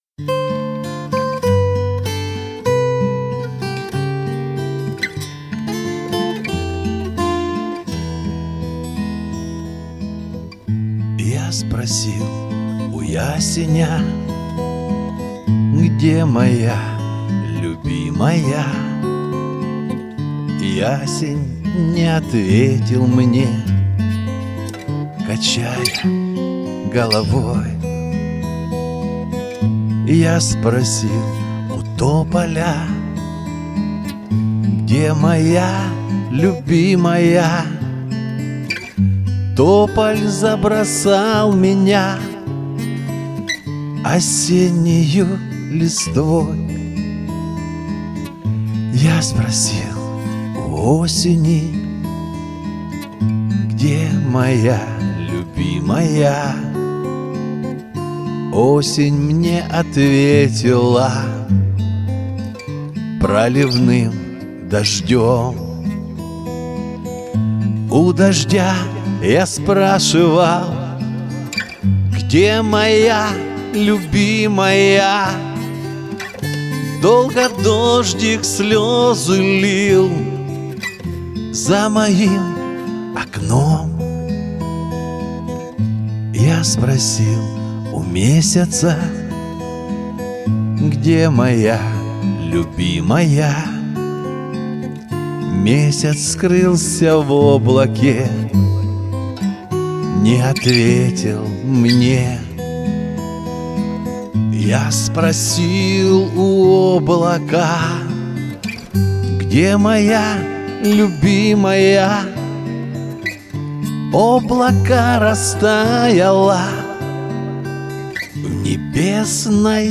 голоса слишком близко